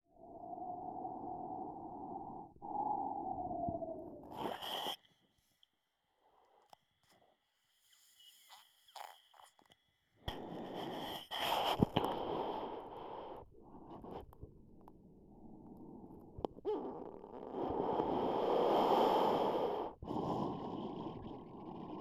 Звук дыхания: слушаем через стетоскоп